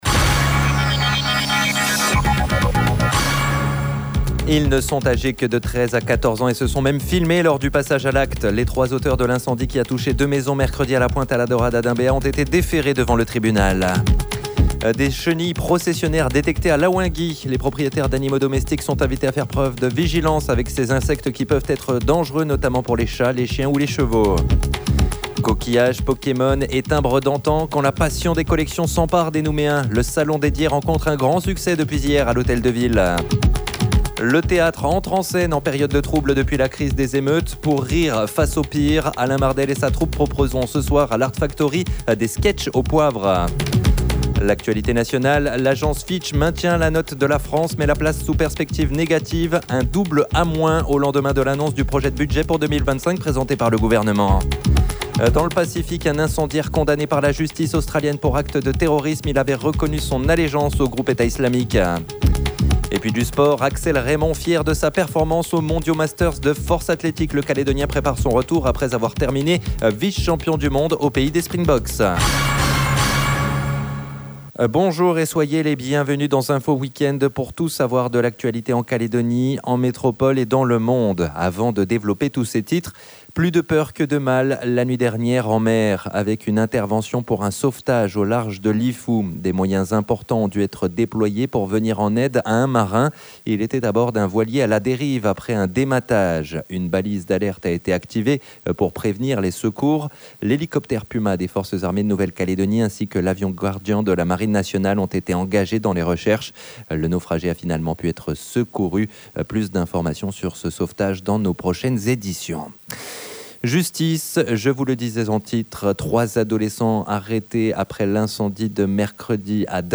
JOURNAL : INFO WEEK END SAMEDI MIDI